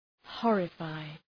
Προφορά
{‘hɔ:rə,faı}